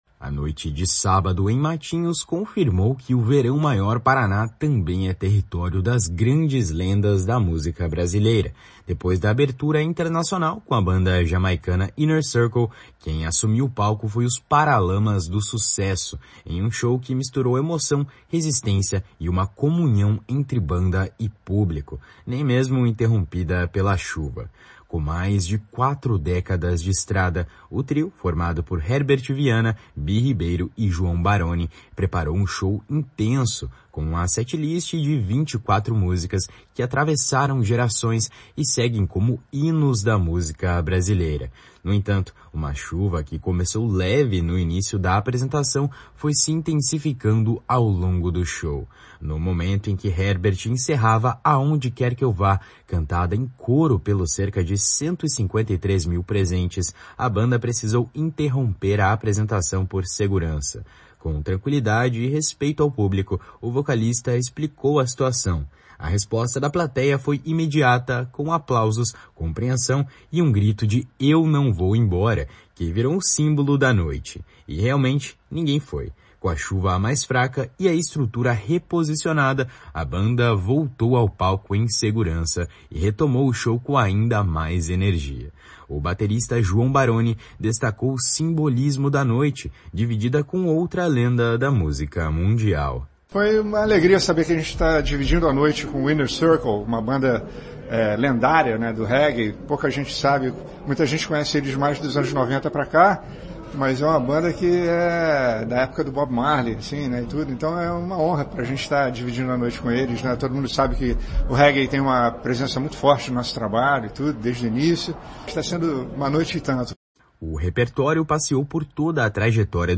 O baterista João Barone destacou o simbolismo da noite, dividida com outra lenda da música mundial. // SONORA JOÃO BARONE //